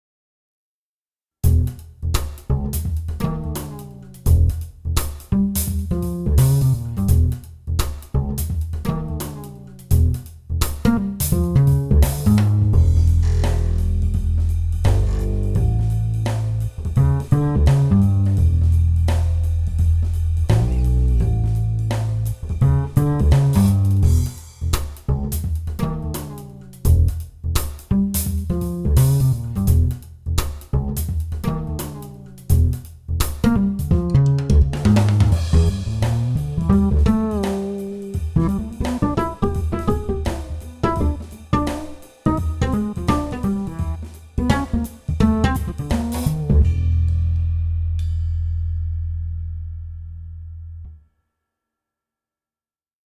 The Manytone Upright Bass includes both a DI soundset and a Mic'd soundset, such that the layering of these soundsets can produce unique tones.
manytone_upright_bass_demo1.mp3